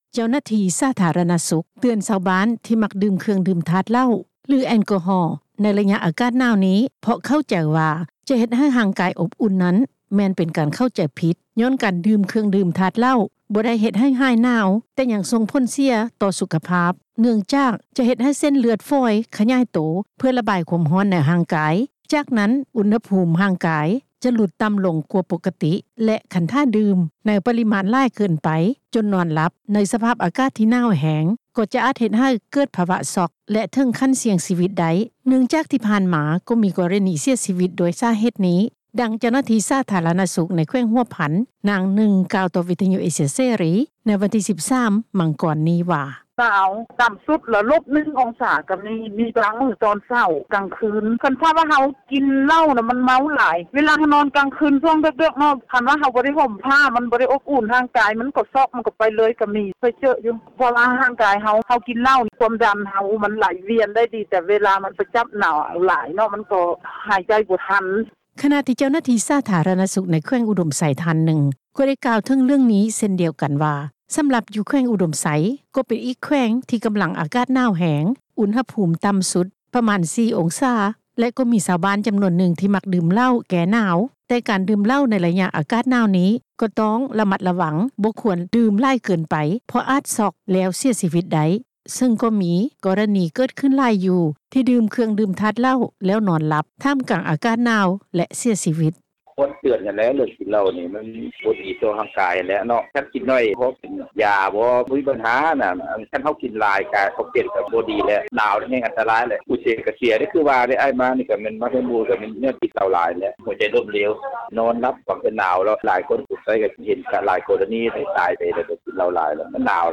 ດັ່ງເຈົ້າໜ້າທີ່ ສາທາລະນະສຸກ ໃນແຂວງຫົວພັນ ນາງໜຶ່ງ ກ່າວຕໍ່ວິທຍຸເອເຊັຽເສຣີ ໃນວັນທີ 13 ມັງກອນ ນີ້ວ່າ: